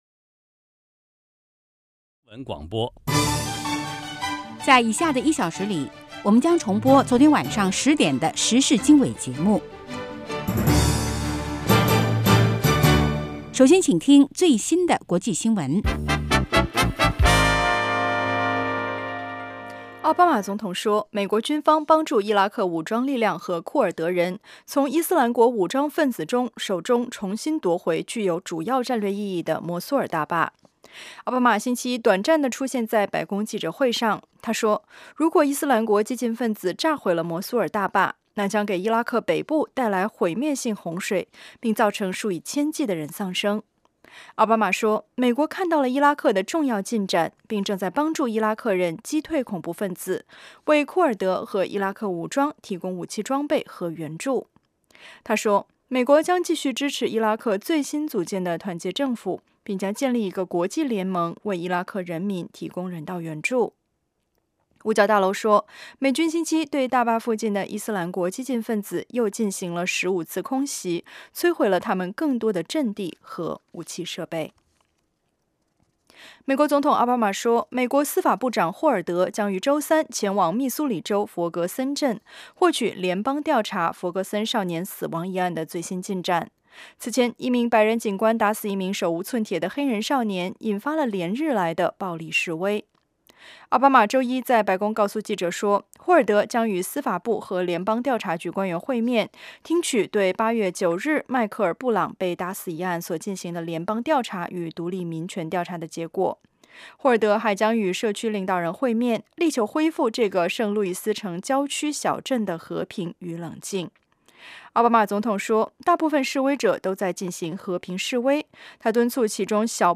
早8-9点广播节目